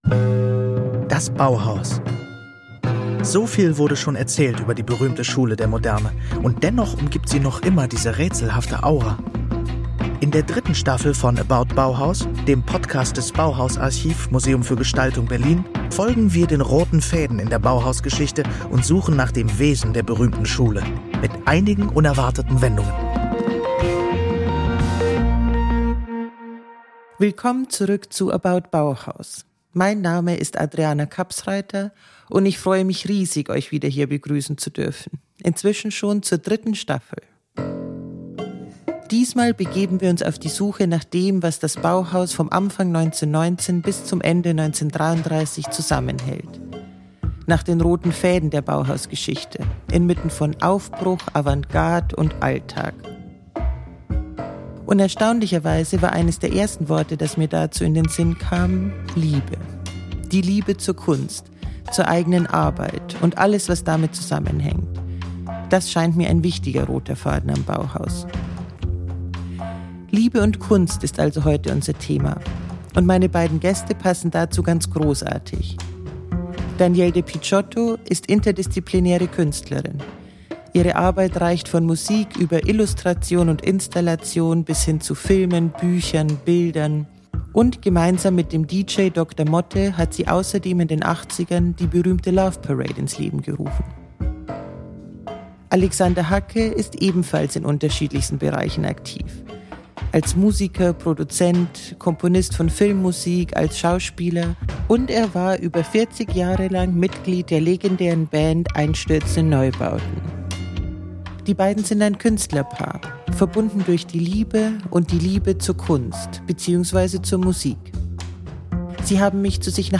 In ihrem Haus am Stadtrand von Berlin erkunden sie gemeinsam Parallelen zwischen der Liebe am Bauhaus, der Leidenschaft für eine Sache und ihrer eigenen künstlerischen Arbeit. Dabei geht es um Idealismus, Gemeinschaft, Nähe – und die Kraft, Neues zu schaffen.